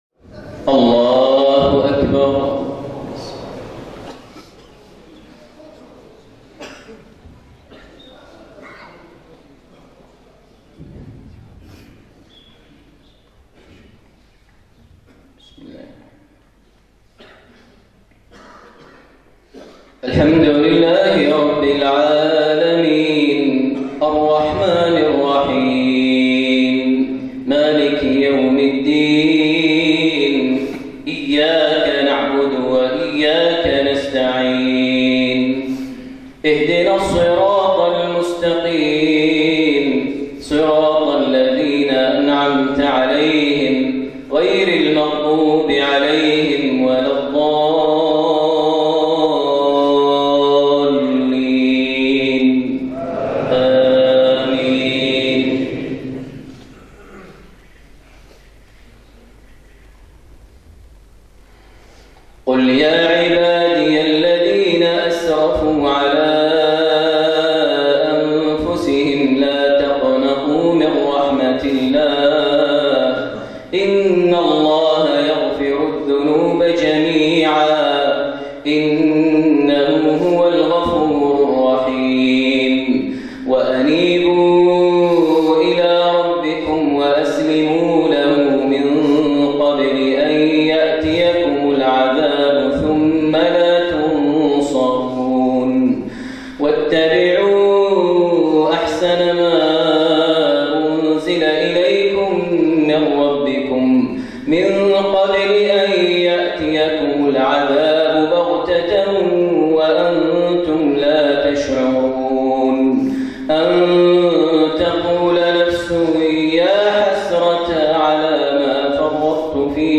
من قيرغيزستان | صلاة العشاء ١٦ رجب ١٤٣٥هـ خواتيم سورة الزمر > زيارة الشيخ ماهر المعيقلي لدولة قيرغيزستان 1435هـ > المزيد - تلاوات ماهر المعيقلي